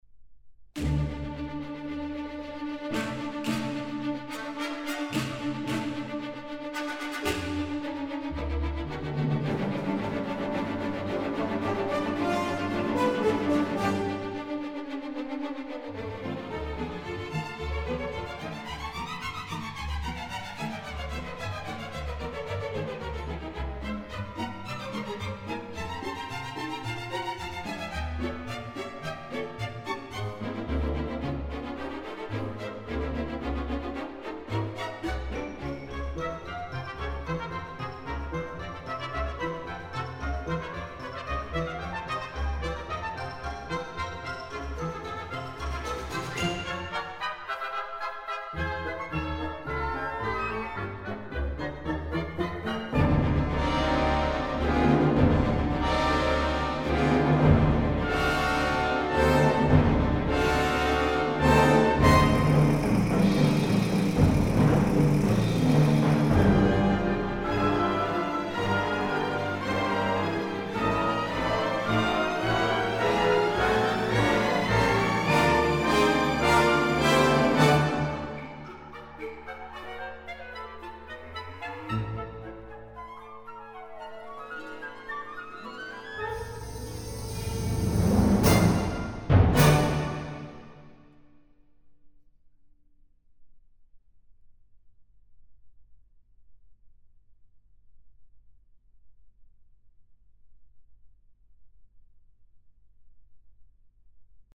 fa sonar l’orquestra esplendorosa
l’estimulant
En prou feines 1:44 de vitalitzant homenatge.